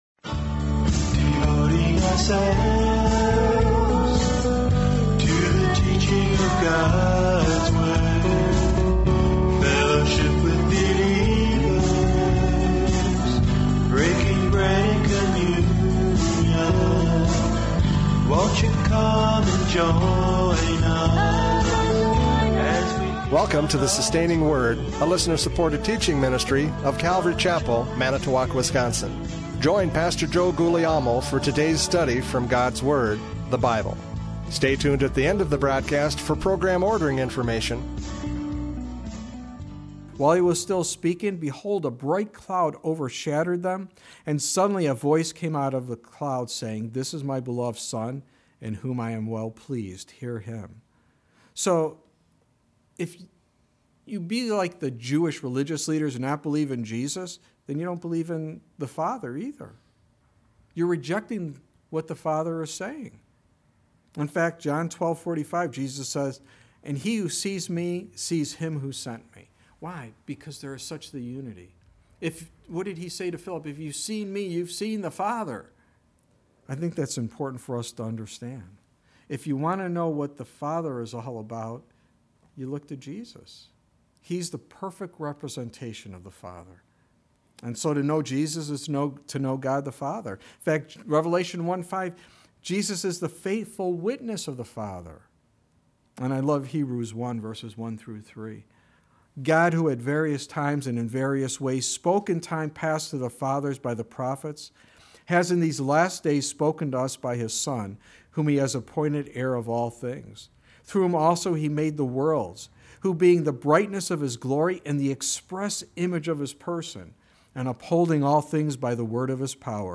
John 12:44-50 Service Type: Radio Programs « John 12:44-50 The Last Call!